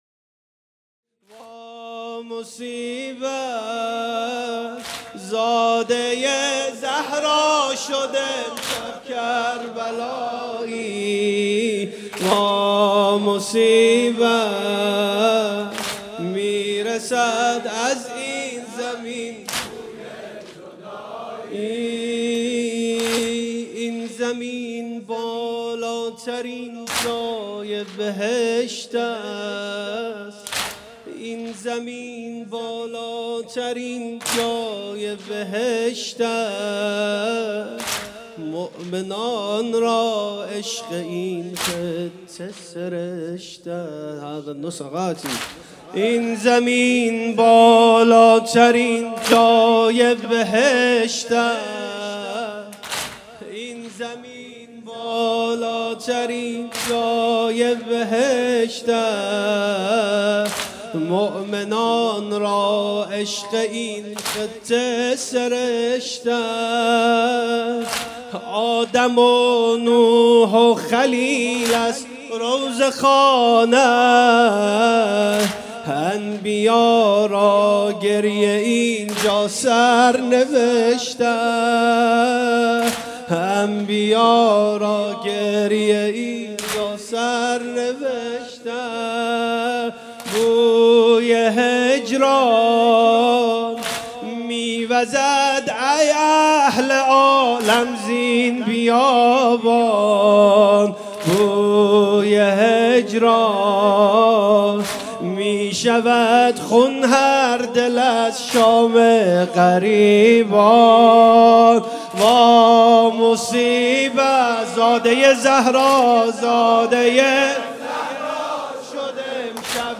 شب دوم محرم 97 - زاده زهرا شد امشب کربلایی